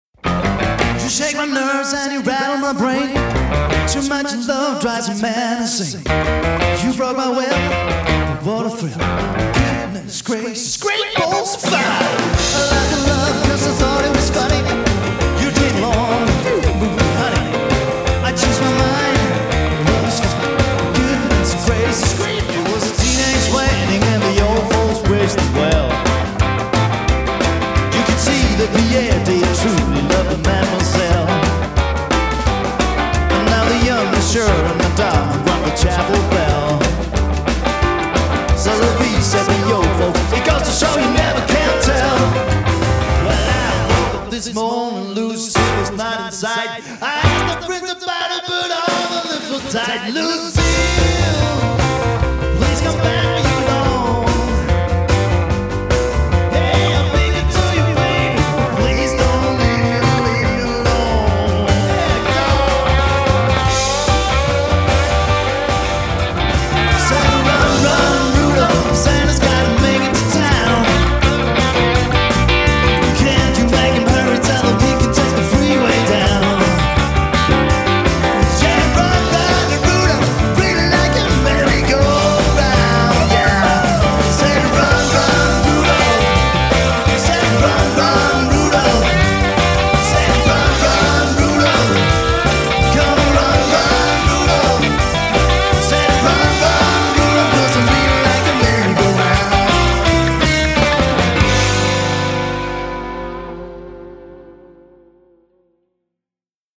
The greatest rock 'n roll of the 50's and 60's